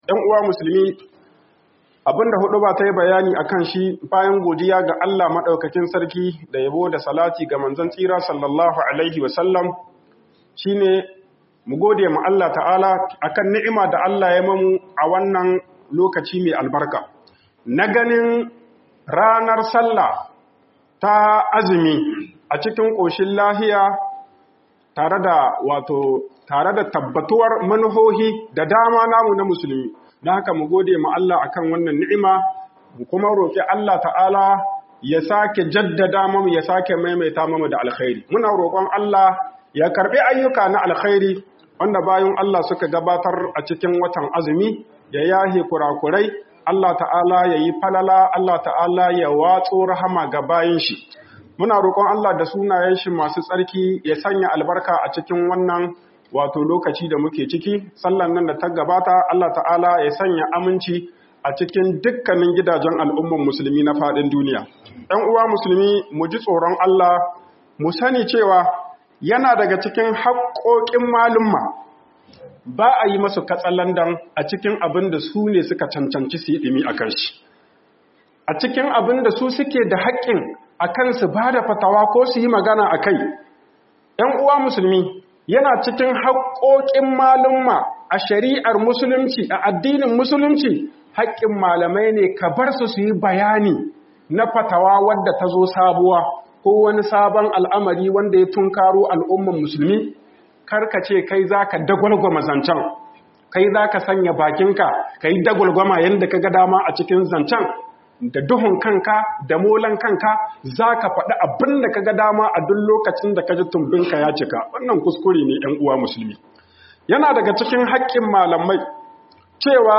YIN FATAWA A ADDININ MUSULUNCI AIKINE NA MANYAN MALAMAI - HUƊUBOBIN JUMA'A